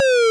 fsDOS_switchOff.wav